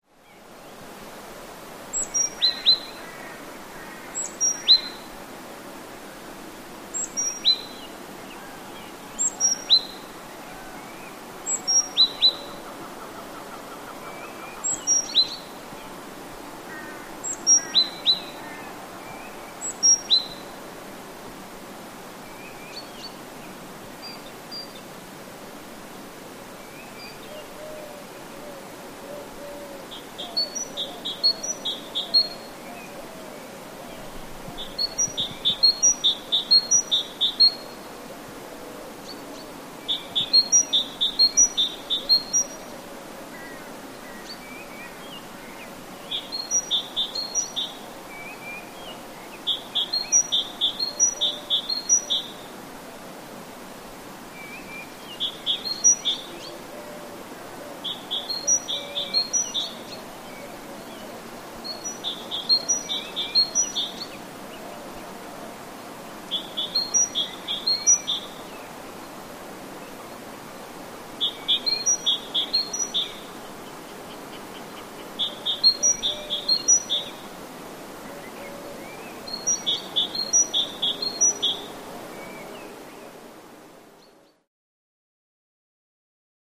Israel Biblical Reserve, Early Morning Exterior Bird Ambience Near Forest Pond